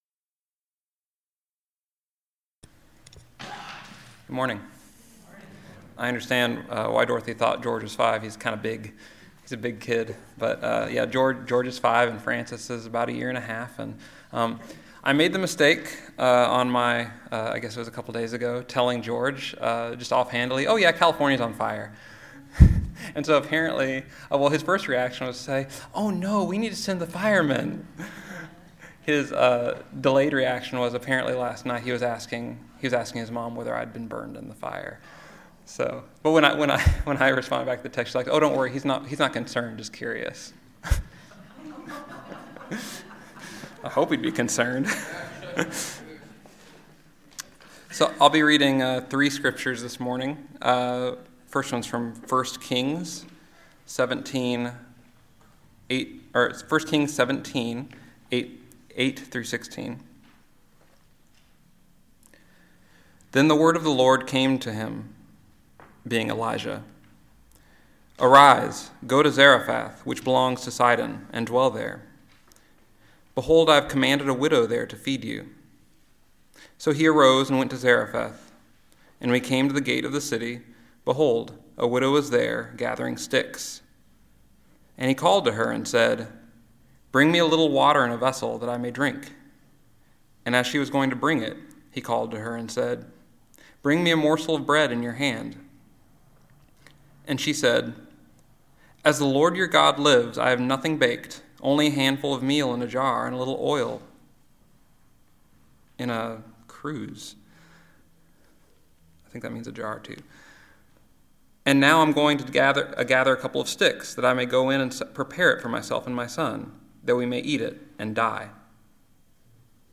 Listen to the most recent message from Sunday worship at Berkeley Friends Church.